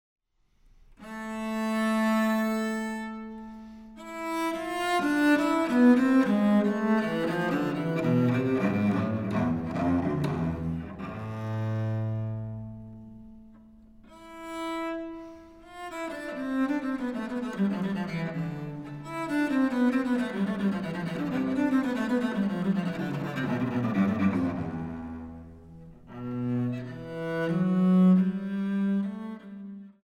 Prelude (Warsaw, MS R221/377)